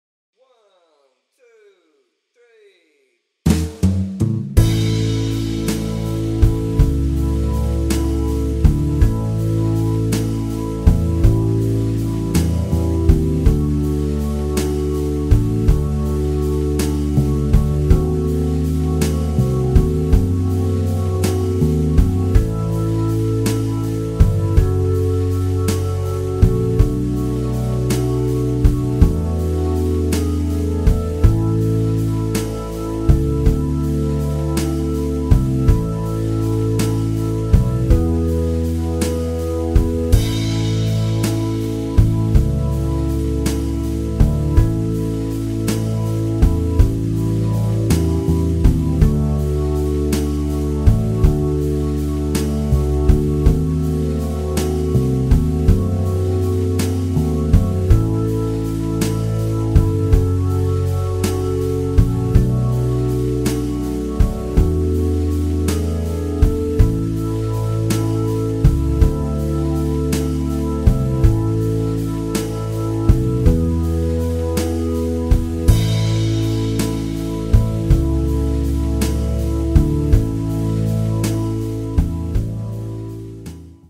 Aihe: Divaritason roots-skaba 304 osallistujat
Tämä menee A:sta ja sointuja on hieman kolmea enemmän.
Tähän tarvitaan herkkyyttä ja tunnetta..